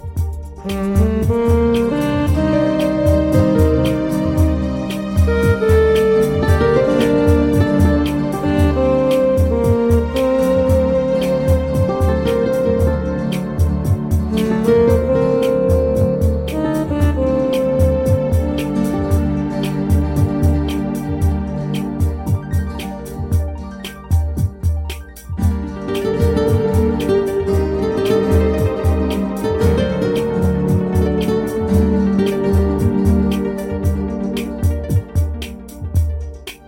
CHILLOUT LOUNGE MUSIC